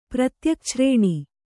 ♪ pratyakchrēṇi